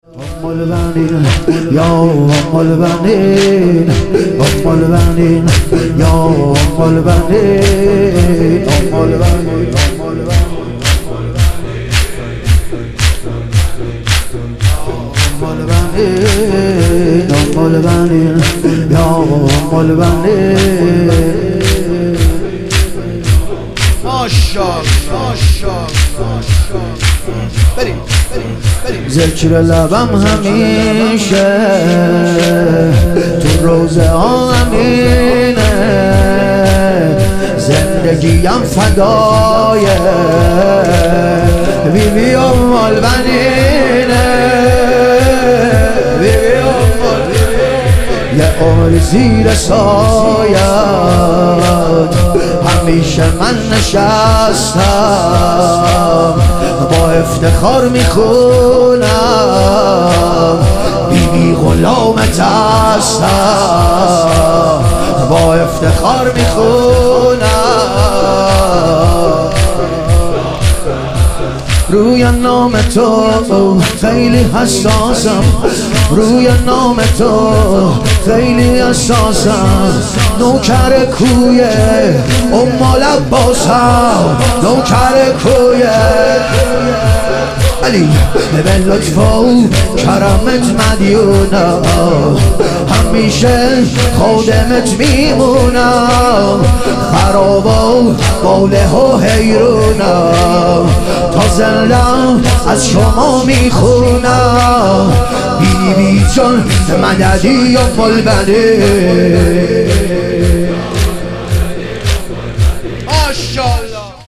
شهادت حضرت ام البنین علیها سلام - تک